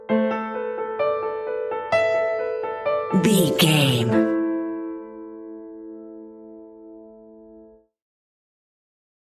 Aeolian/Minor
tension
ominous
dark
suspense
haunting
eerie
stinger
short music instrumental
horror scene change music
mysterious